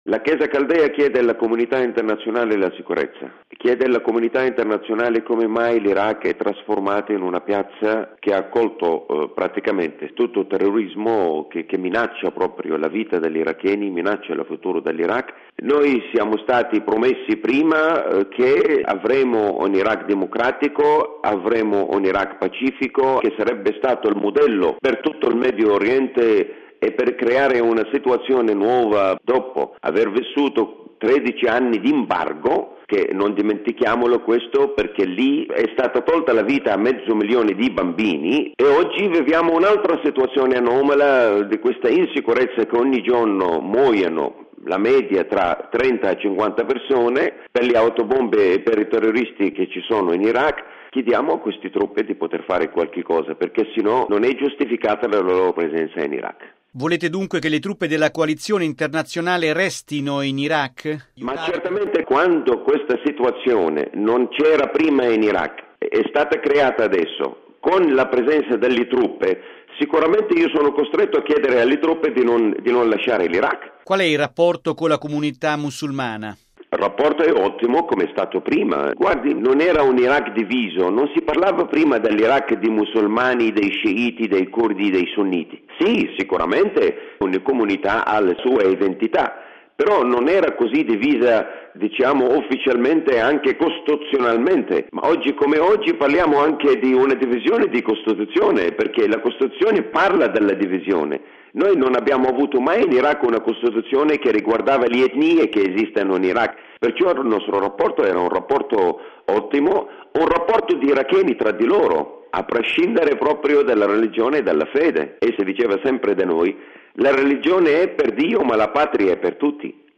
Ma cosa chiede la Chiesa caldea alla comunità internazionale in questo difficile momento per l’Iraq?